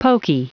Prononciation du mot pokey en anglais (fichier audio)
Prononciation du mot : pokey